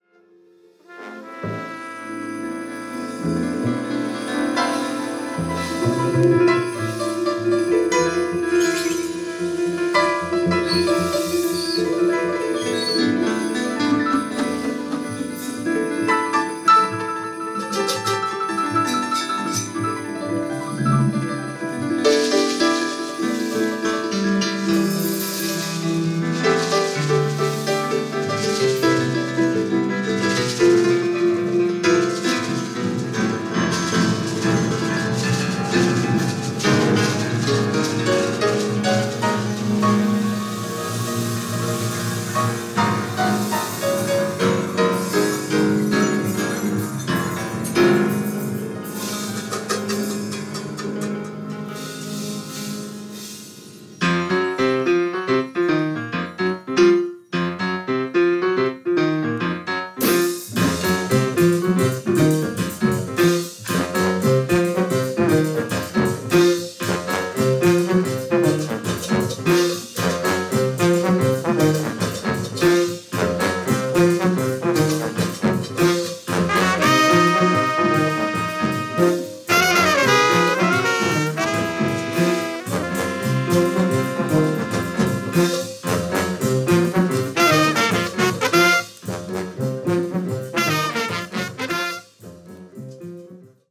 Piano
Saxophone
Drums
Bass
Trumpet